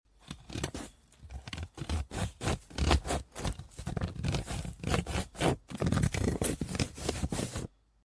Brown Onion Face Cutting 🔪🧅 Sound Effects Free Download